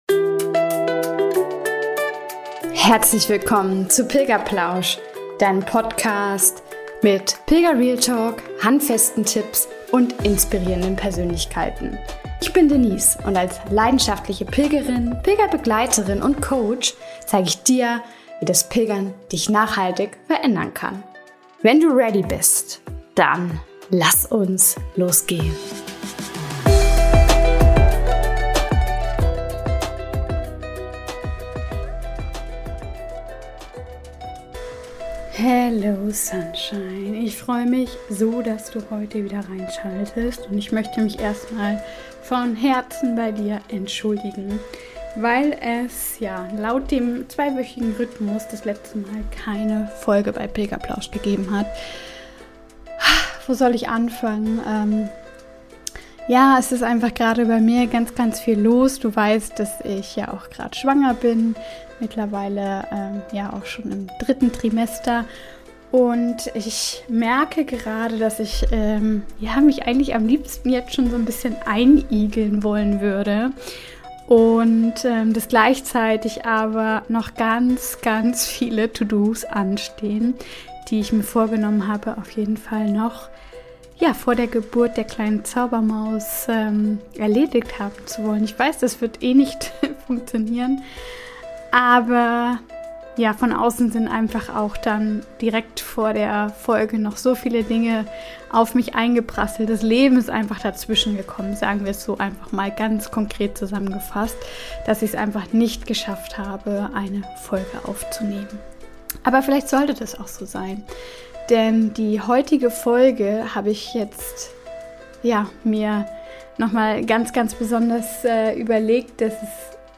Details zur Folge: Eine ganz persönliche Einleitung, warum mich dieses Thema gerade so bewegt. Die geführte Walking Meditation beginnt bei Minute 09:10.